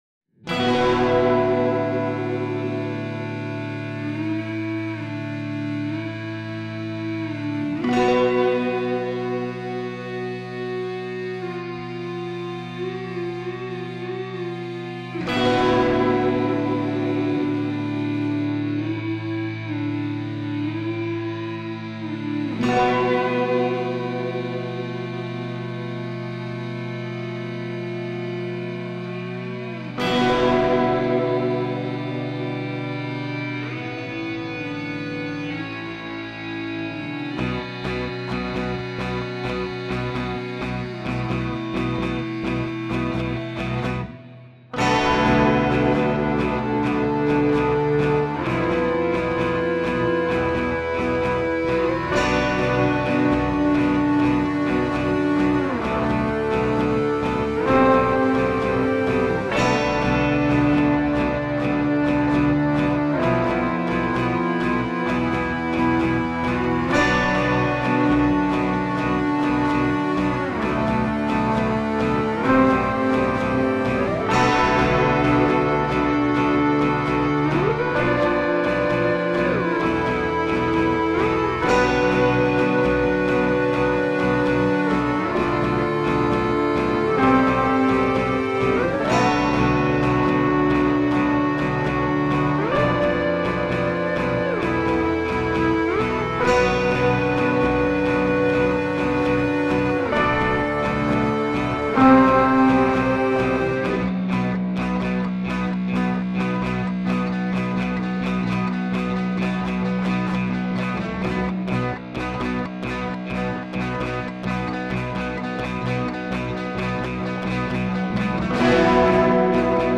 4/4, tempo = 65 puis 130.
Cette version s'arrête un peu après la partie "Fuzz", au moment où nous partirions en solos...
[0'00] intro (la rythmique 1 commence dans les deux dernières mesures)
[1'44] Part 2 avec la rentrée de la rythmique 2
[2'21] "Fuzz" sur 2 cycles